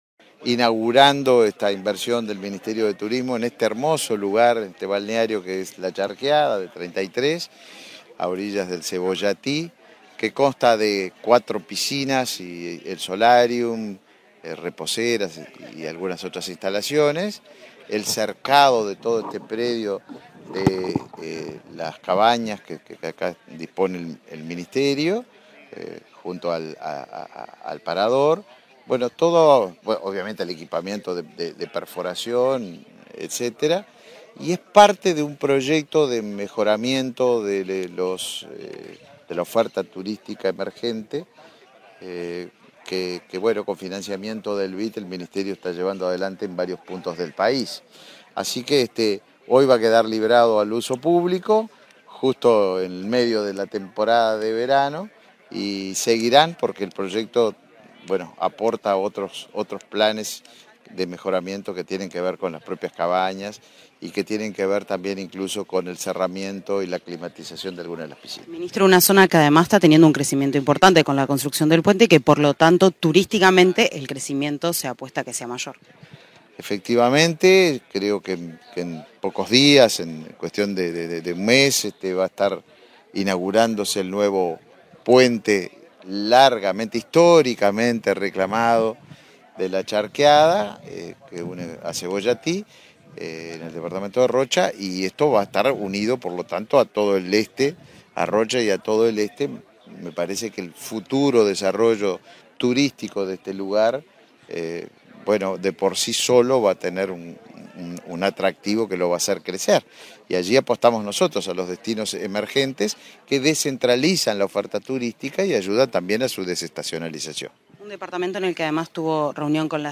Declaraciones del ministro de Turismo, Tabaré Viera
Declaraciones del ministro de Turismo, Tabaré Viera 10/02/2023 Compartir Facebook X Copiar enlace WhatsApp LinkedIn Tras la inauguración de un proyecto turístico en La Charqueada, este 10 de febrero, el ministro de Turismo, Tabaré Viera, realizó declaraciones a la prensa.